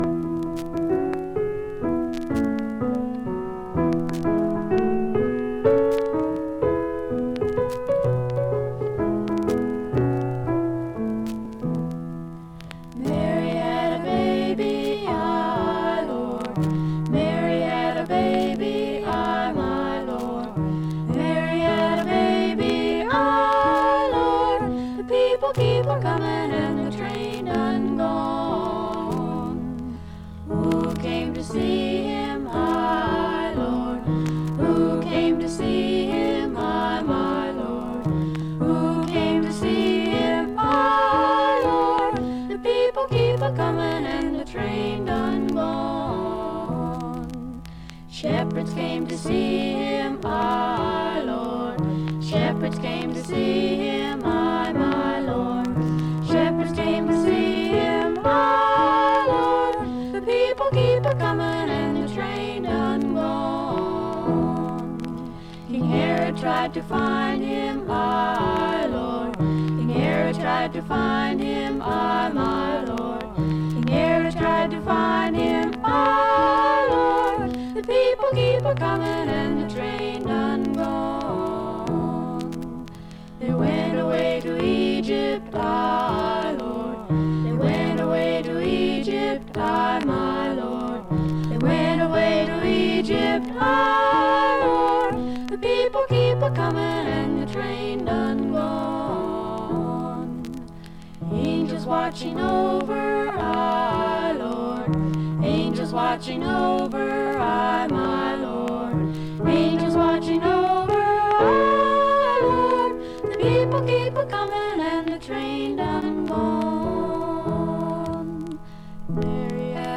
To start, something quiet:
With their close, pure, vibratoless harmony
spiritual